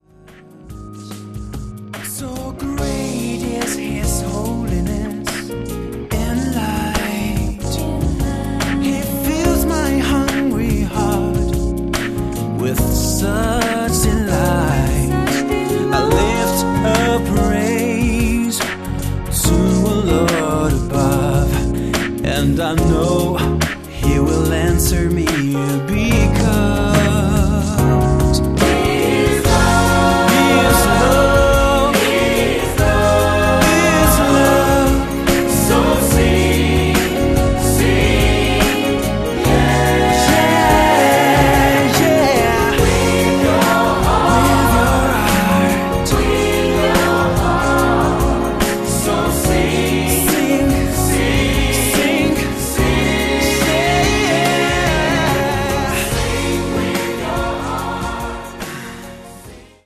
tenore
contralto
soprano
pianista arrangiatore
Drums